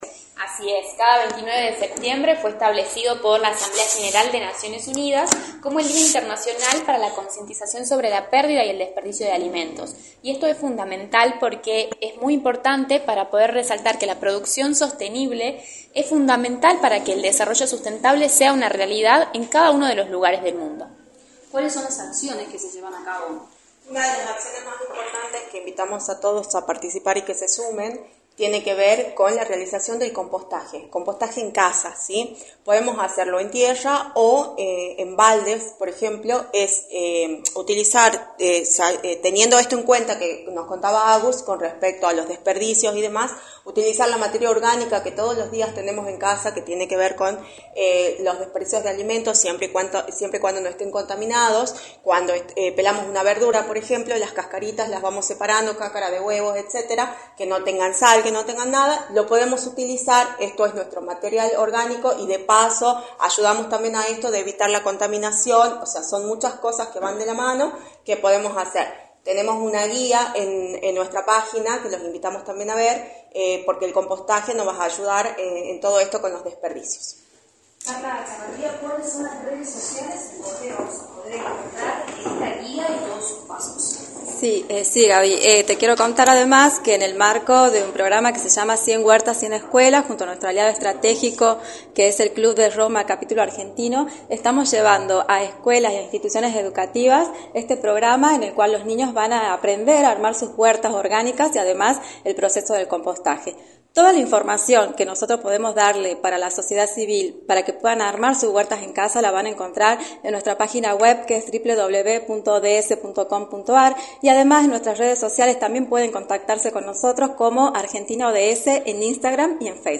“La Asamblea General de la ONU establece este día, el cual es fundamental para resaltar que la producción sostenible es fundamental para que el desarrollo sustentable sea una realidad en cada lugar del mundo” señalaron en entrevista para “La Mañana del Plata”, por la 93.9.